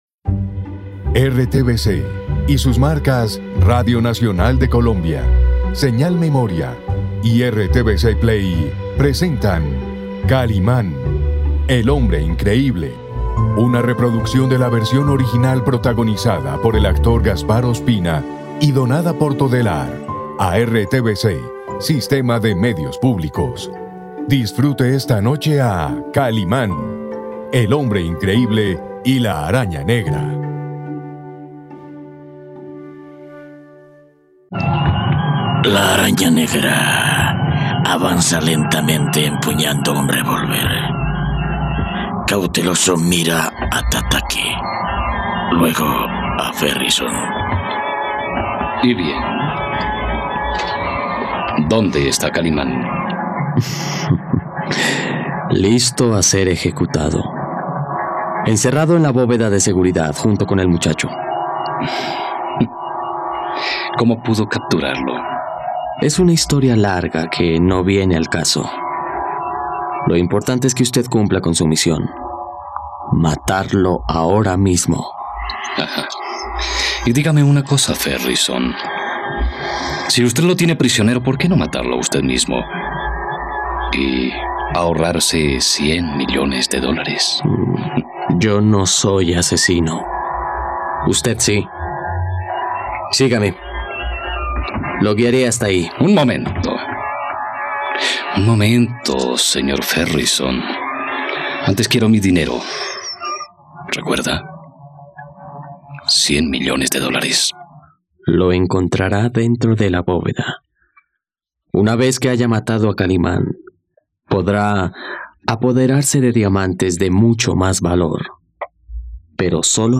..Llegó el final de la radionovela de 'Kalimán, el hombre increíble y la Araña negra'.